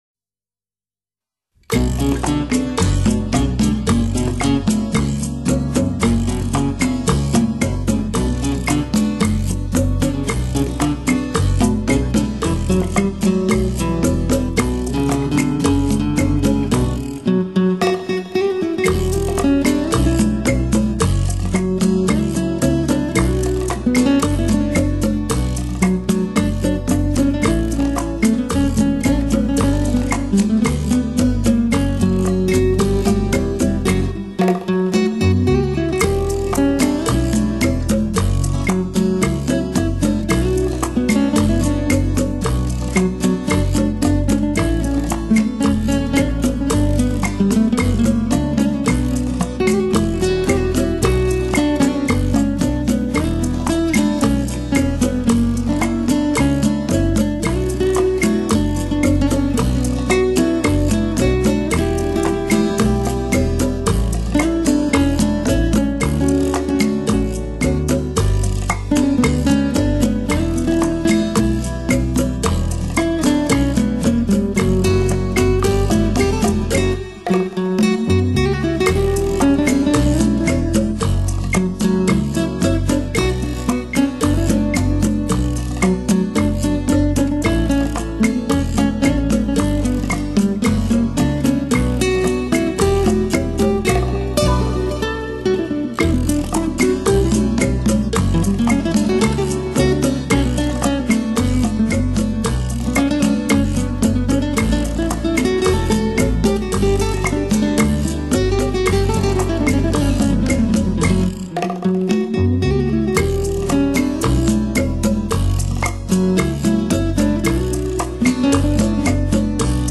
Genre: Instrumental | Latin | Easy Listening | Guitar